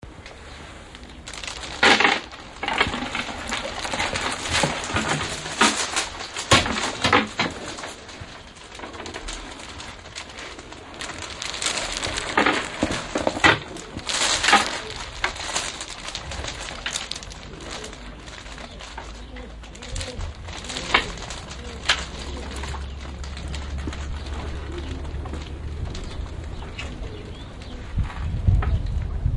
双耳录音（用木珠做的窗帘沙沙作响），用OKM话筒录音